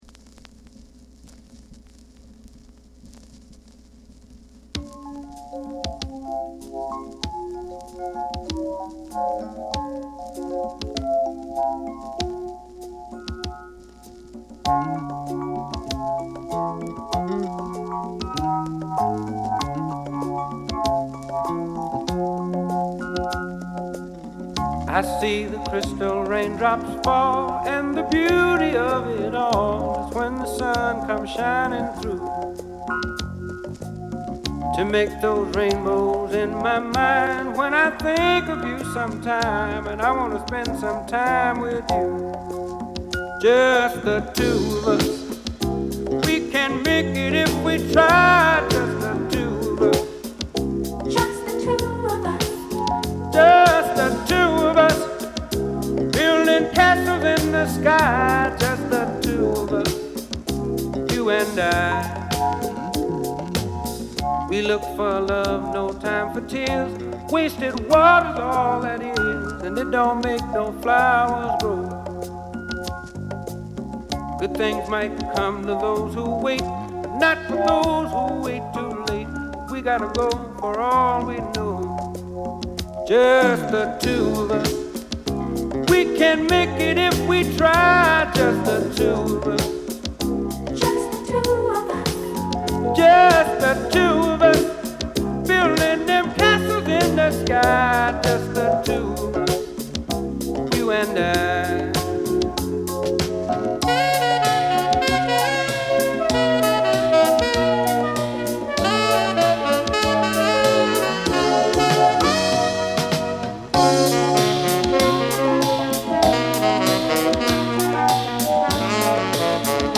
Genre: Soul / R&B / Quiet Storm